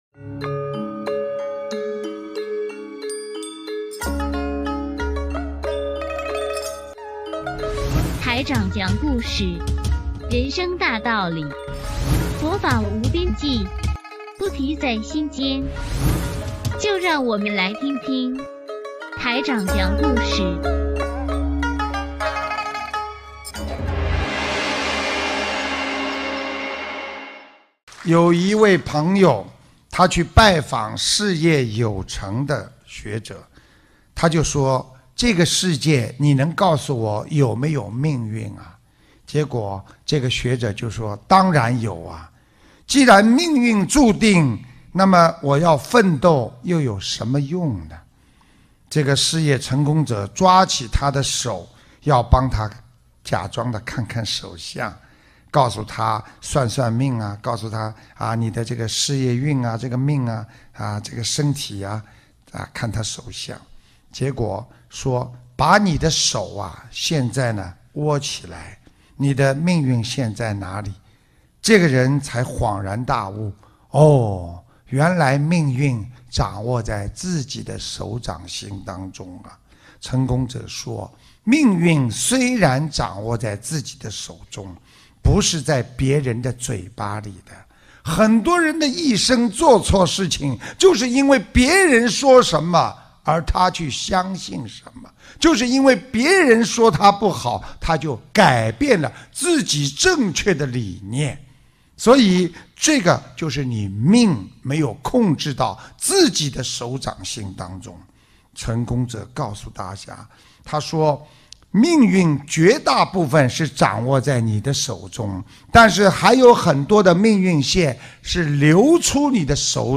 音频：学佛改命·师父讲小故事大道理